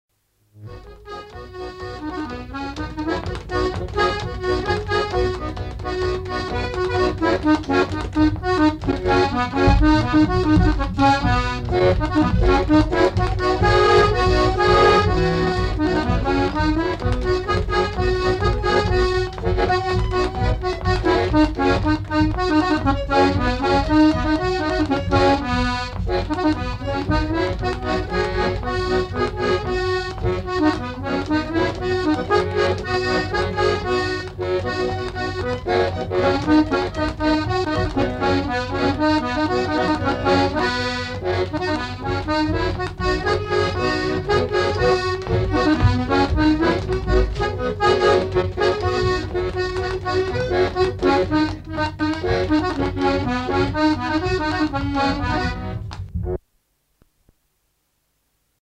Lieu : Polastron
Genre : morceau instrumental
Instrument de musique : accordéon diatonique
Danse : youska